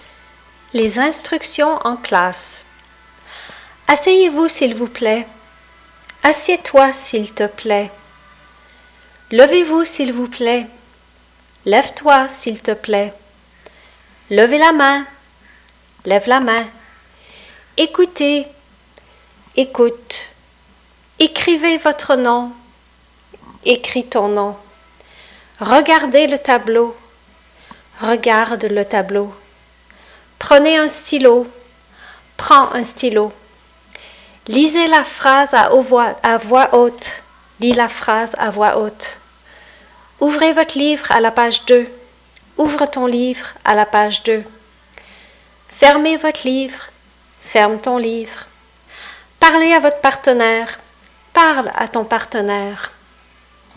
Note on pronunciation: when you listen to the vocabulary, pay attention on how most final consonants of words are NOT pronounced.